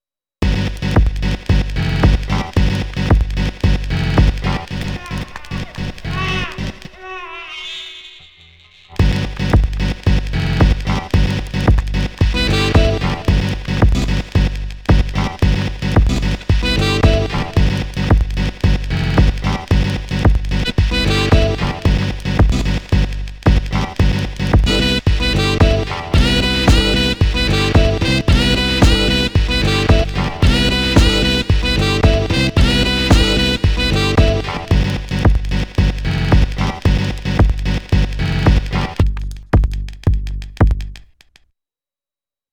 put a few hours into this tonight. a4 (kick) + ot (with only the source material). thanks for the opportunity to get chopping and arranging!
I’ve never made anything that sounds this much like the old MODs I used to listen to :rofl:
I feel like I’m waiting for a cracked Amiga game to load.